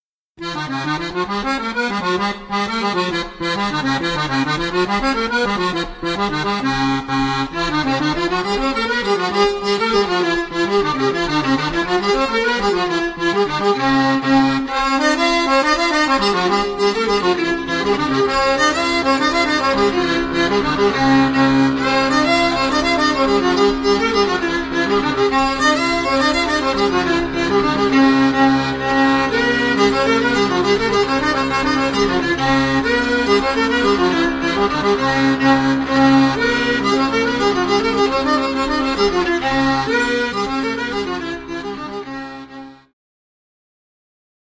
skrzypce
akordeon, flety proste, whistles, cytra
mandolina, gitara akustyczna